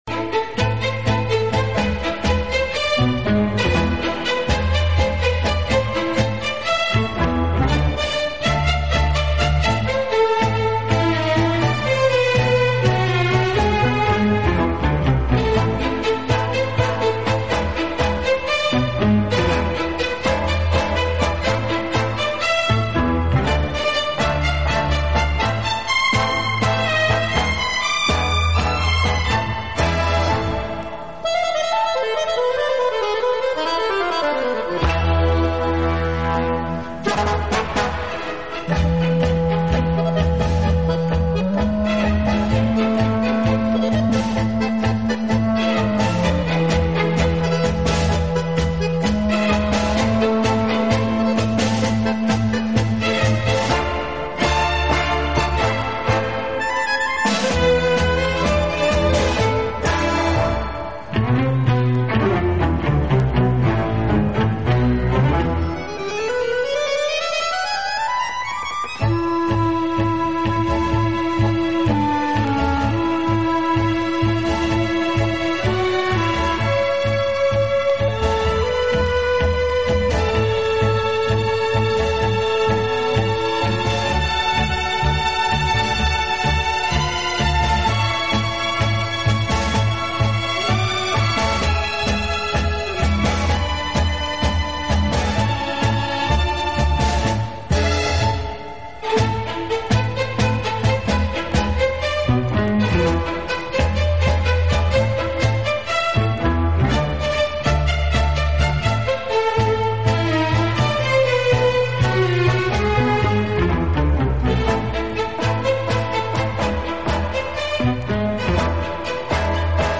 【轻音乐专辑】